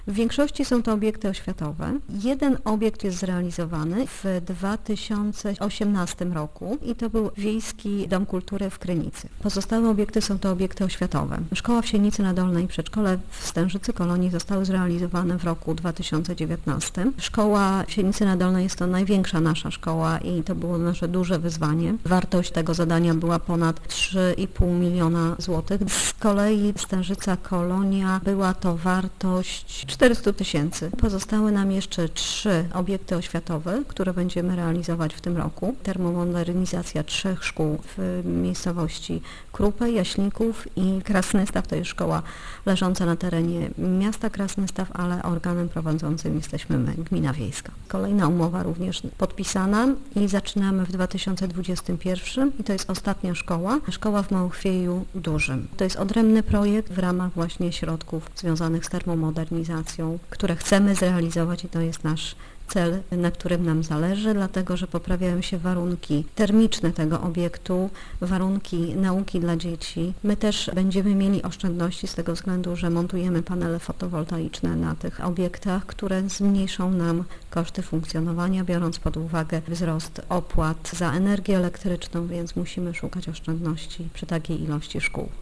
Wójt gminy Krasnystaw Edyta Gajowiak-Powroźnik podkreśla, że samorząd pokryje tylko niewielką część kosztów robót, ponieważ na resztę pozyskał pieniądze z Unii Europejskiej, które zostaną wykorzystane łącznie na sześć zadań: